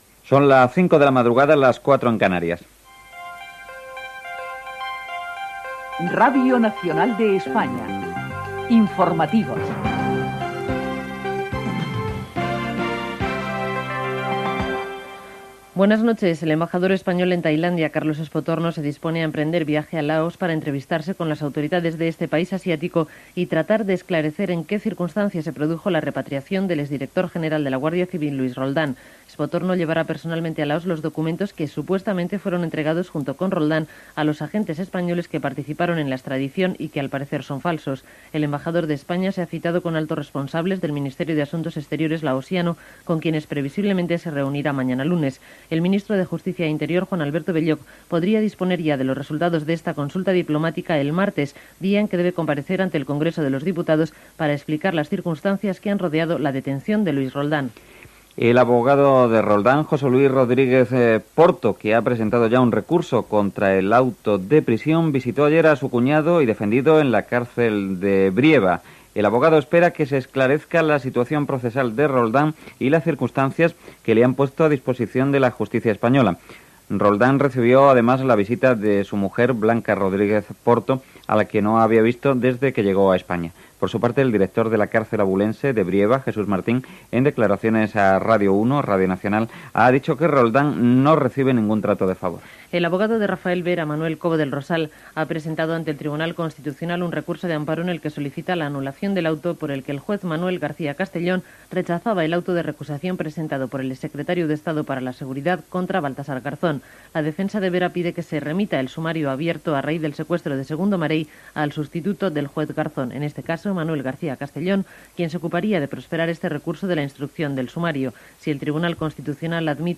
Hora, careta dels serveis informatius, expatriació de Luis Roldán, recusació presentada per Rafael Vera, tractat Perú Equador.
Informatiu